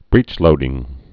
(brēchlōdĭng)